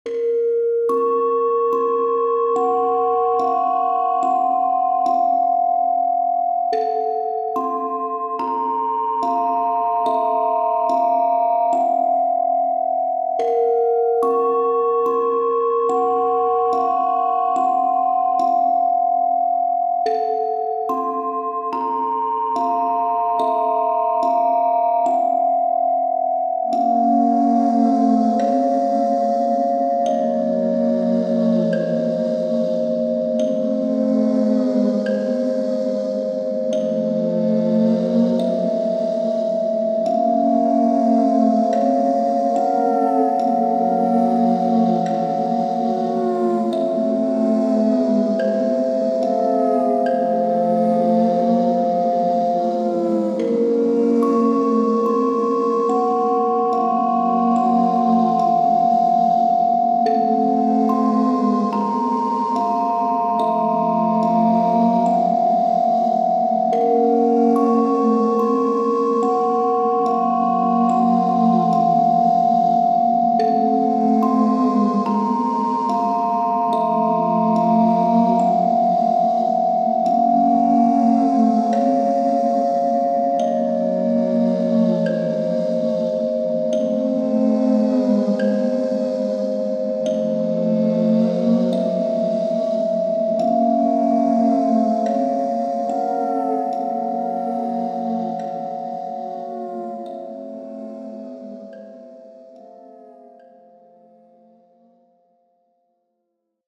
怪異系のホラーをイメージしたBGM。 急に怖がらせるような展開はなく、淡々としたマイルドなホラーBGMになっている。